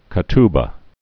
(kə-tbə, kə-t-bä)